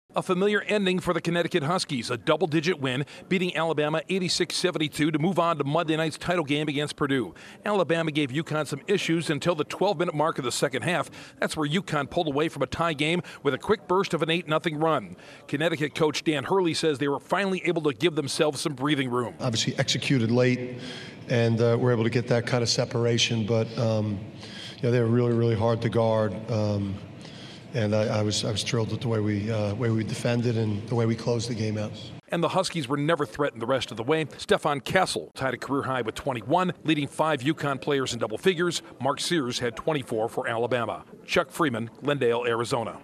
UConn is one win away from an NCAA championship repeat. Correspondent